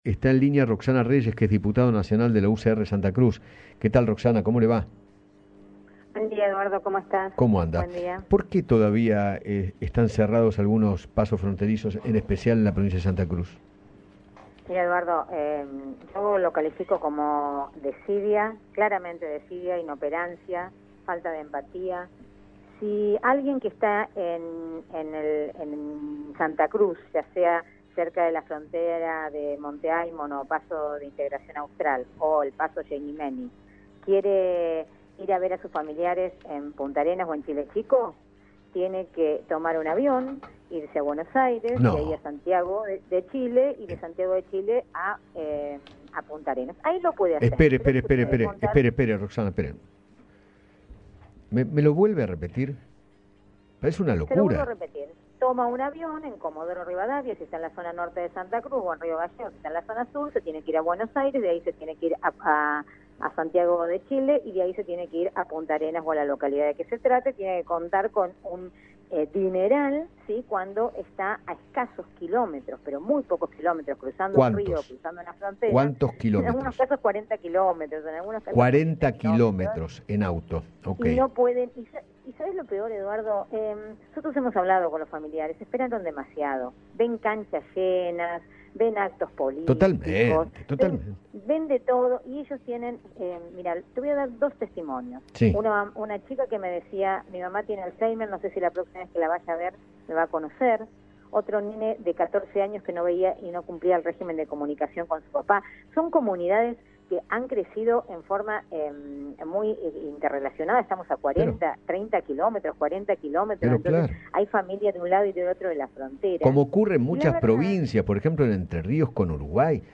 Eduardo Feinmann conversó con la diputada nacional, Roxana Reyes, y con el concejal de Chile Chico, Mario Figueroa, sobre la muerte de un hombre de nacionalidad chilena, que intentó cruzar un río fronterizo para ver a su familia, y se refirieron al prolongado cierre de fronteras.